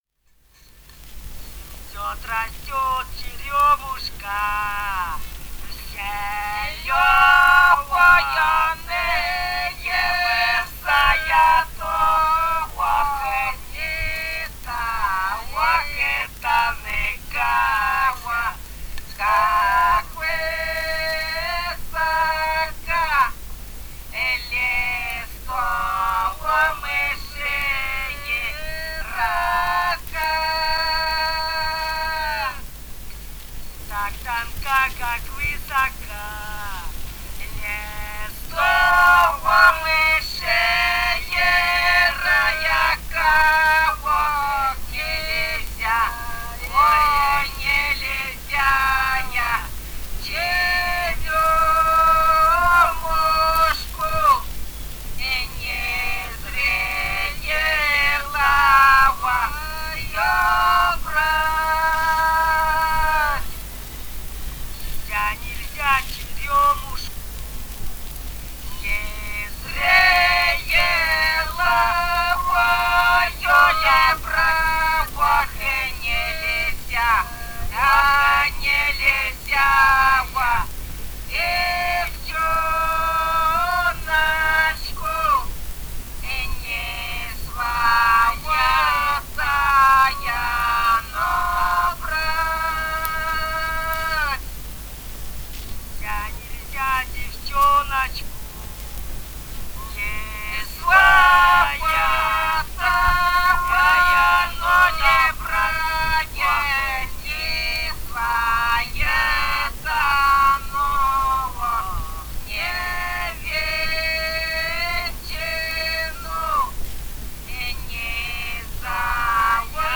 «Цветёт, растёт черёмушка» (лирическая беседная на свадьбе).
Бурятия, с. Желтура Джидинского района, 1966 г. И0904-14